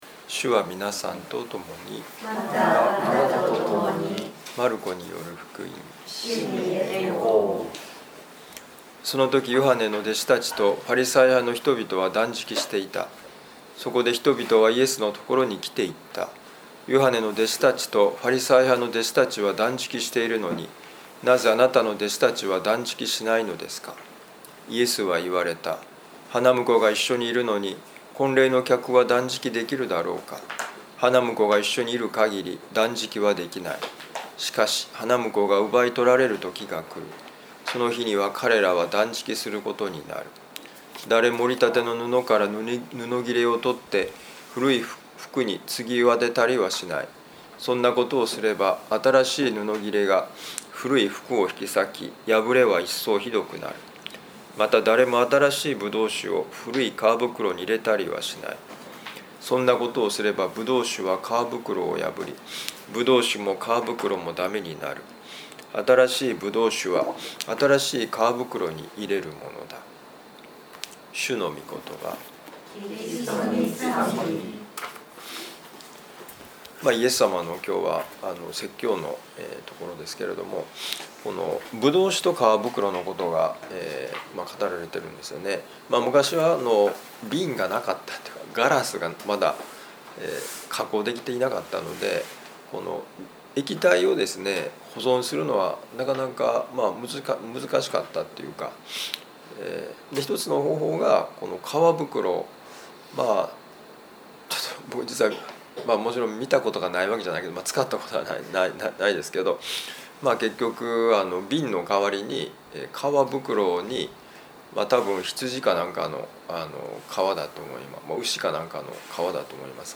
【ミサ説教】
マルコ福音書2章18-22節「新しい皮袋になる」2025年1月20日旅の里いやしのミサ